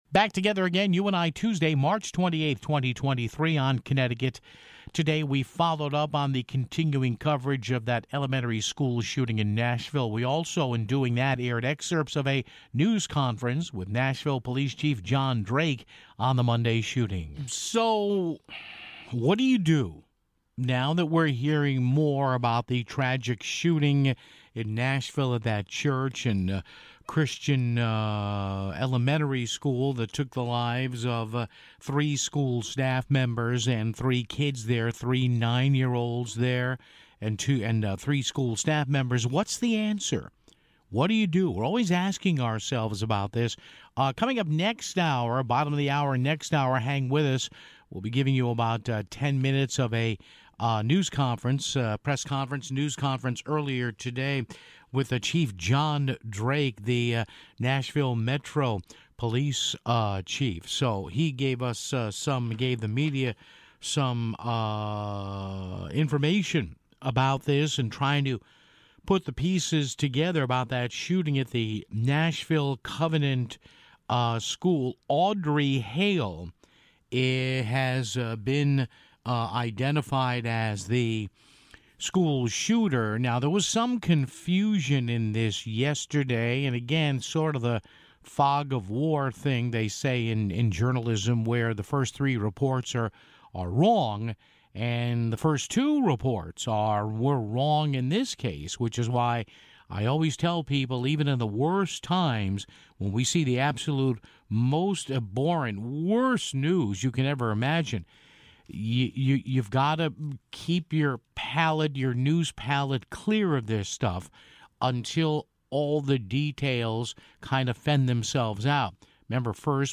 We also aired excerpts of a news conference with Nashville Police Chief John Drake on Monday's shooting (12:36).